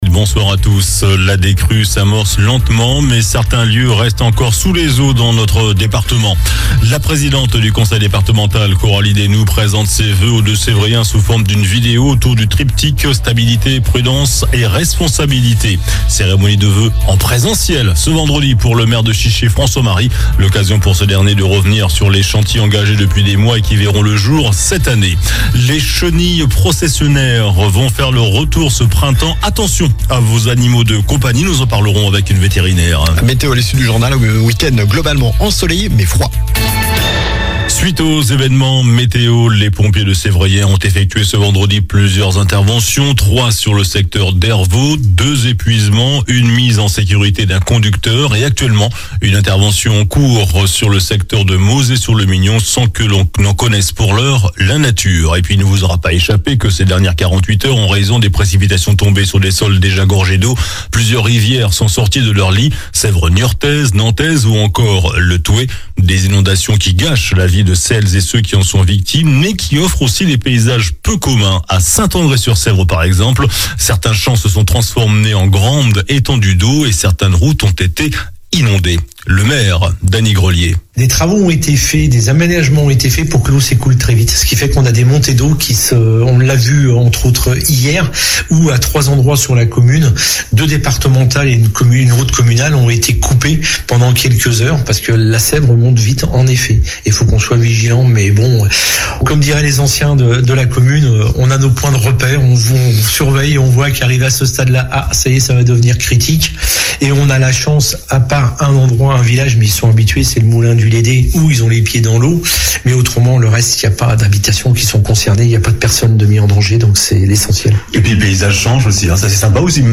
JOURNAL DU VENDREDI 10 JANVIER ( SOIR )